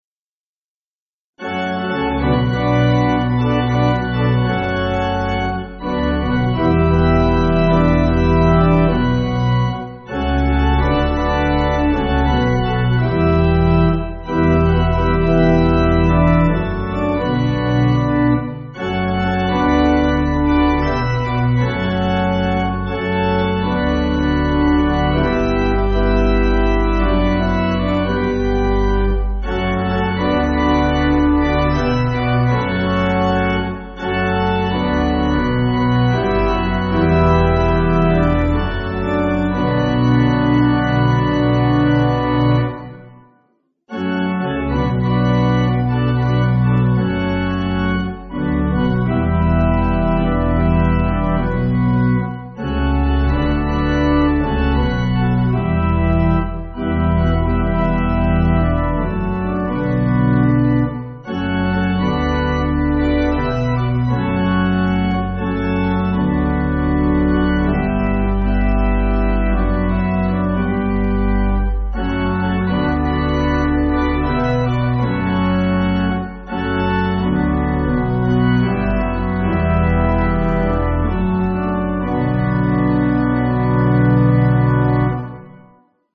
Organ
(CM)   5/Bm